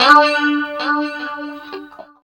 29 GUIT 6 -L.wav